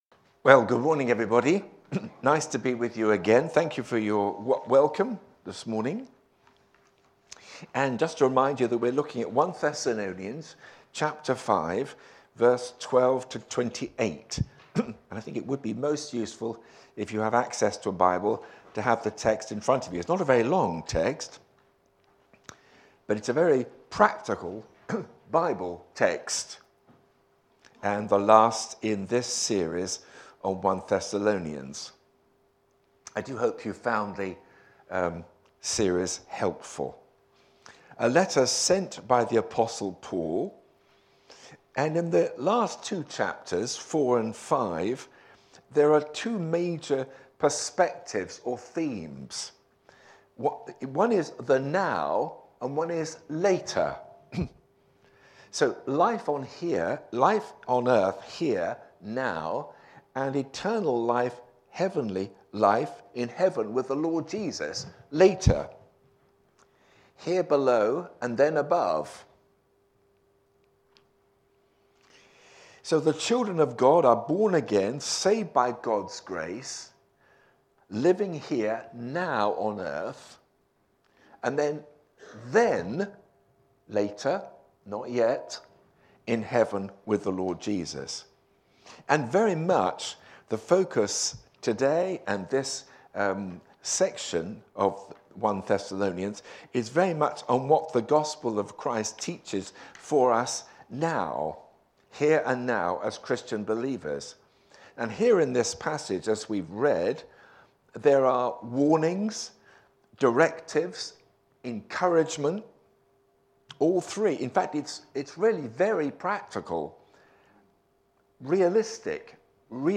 Media for a.m. Service on Sun 23rd Feb 2025 10:30
Theme: Sermon